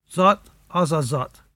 [kUK-ka: at noo kukka]